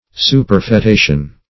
Superfetation \Su`per*fe*ta"tion\, n. [Cf. F. superf['e]tation.]